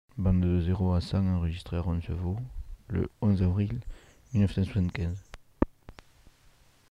Annonce de la date
Aire culturelle : Savès
Genre : parole